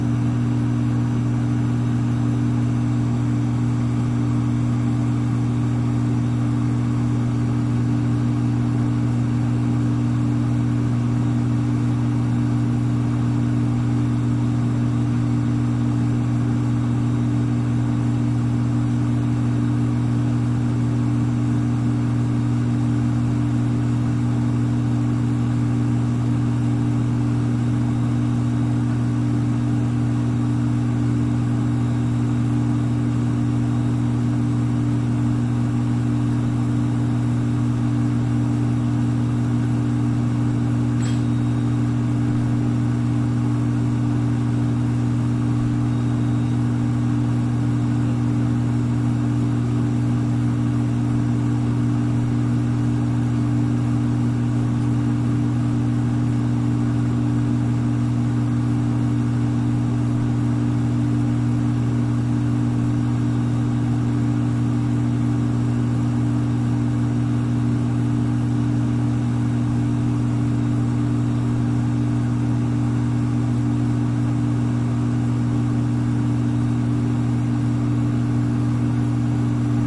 随机的 " 冰箱压缩机旧的气泡关闭
描述：冰箱压缩机老泡状close.flac
标签： 关闭 冰箱 压缩机 香槟
声道立体声